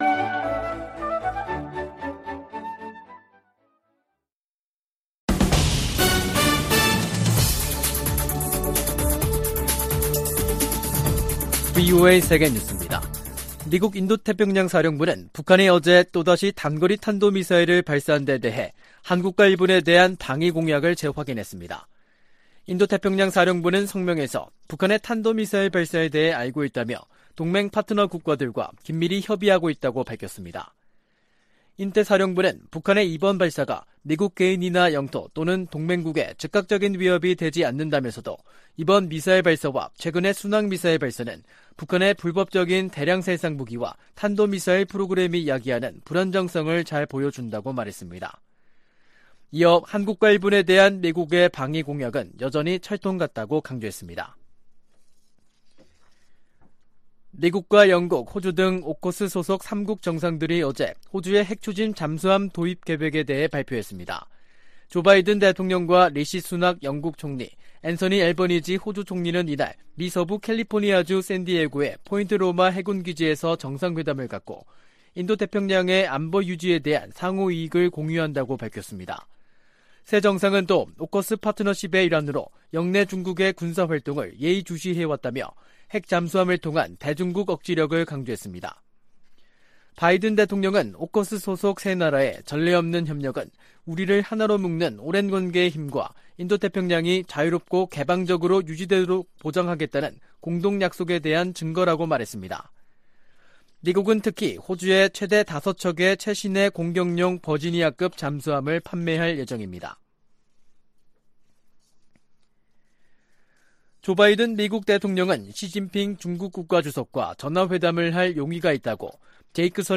VOA 한국어 간판 뉴스 프로그램 '뉴스 투데이', 2023년 3월 14일 3부 방송입니다. 미국과 한국이 ‘자유의 방패’ 연합훈련을 실시하고 있는 가운데 북한은 미사일 도발을 이어가고 있습니다. 백악관은 한반도 안정을 저해하는 북한의 어떤 행동도 용납하지 않을 것이라고 경고했습니다. 한국을 주요 7개국(G7)에 포함시키는 방안을 추진해야 한다는 제안이 나온 데 전직 주한 미국대사들은 환영의 입장을 나타냈습니다.